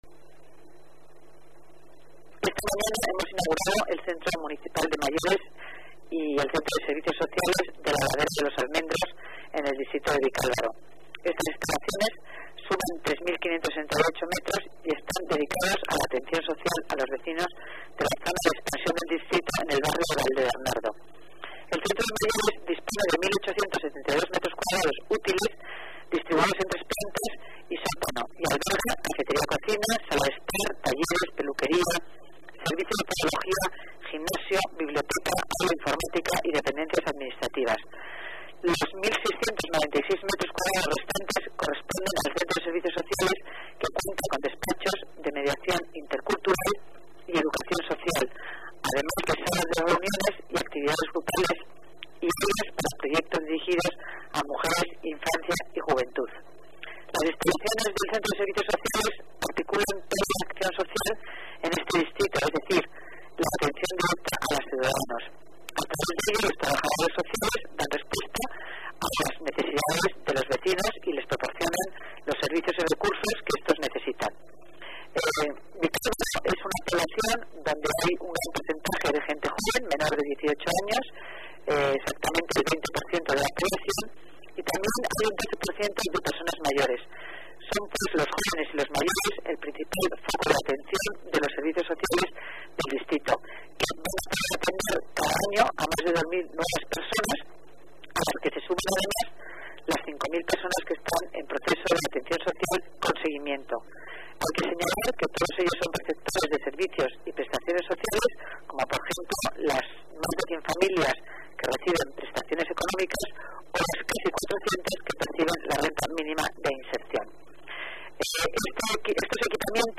Nueva ventana:Declaraciones de la delegada de Familia y Servicios Sociales. Concepción Dancausa